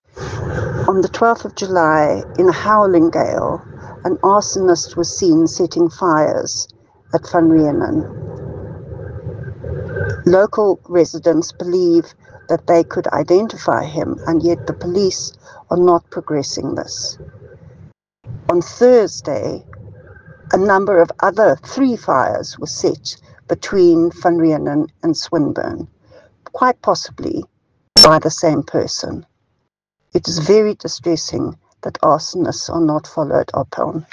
English soundbite by Cllr Alison Oates,